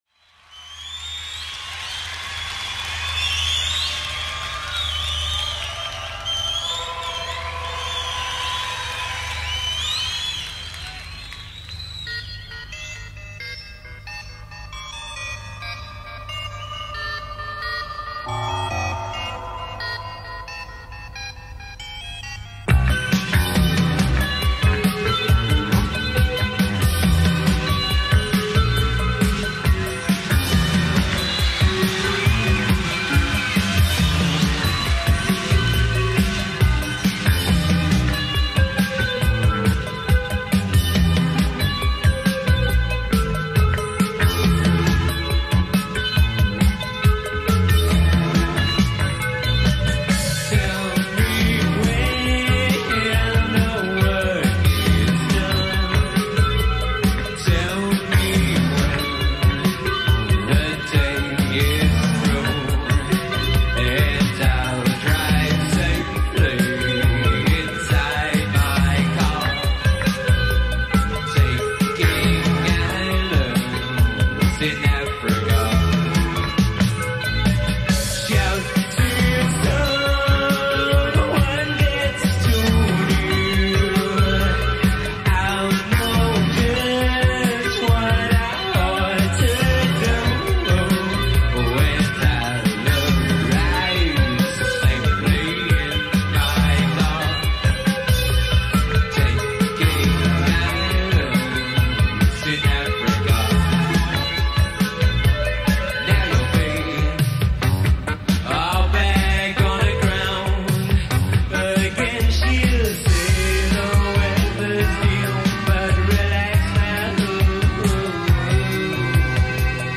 bass guitar
lead guitar
electronic music